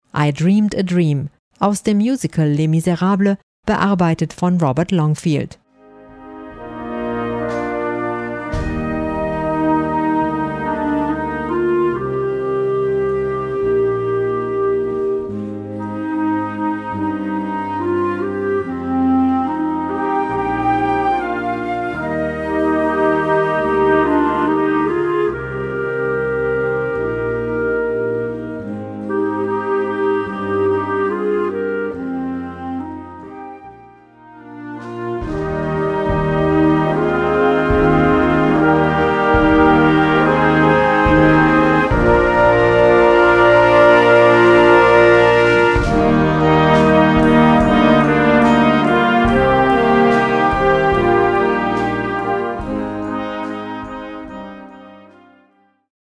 Gattung: Jugendmusik
Besetzung: Blasorchester